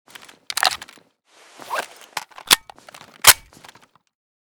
mp7_reload_empty.ogg.bak